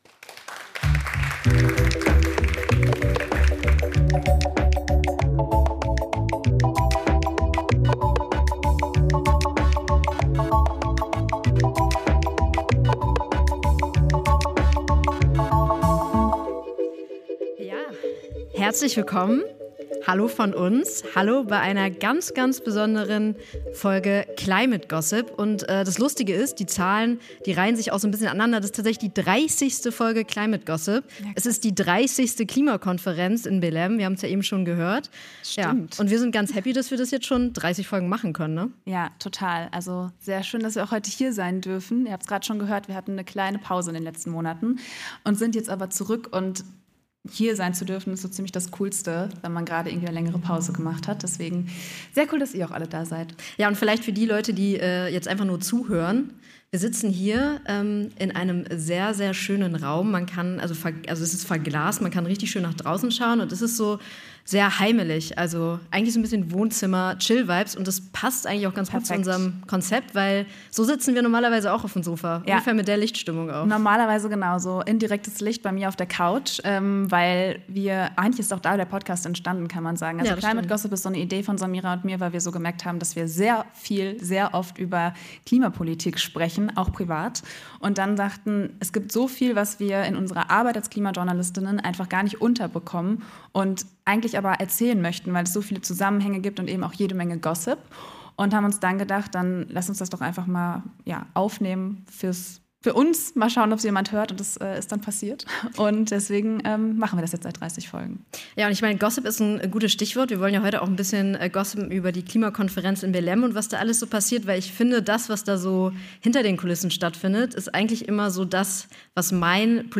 #30 What happened in Belém? LIVE Podcast ~ Climate Gossip Podcast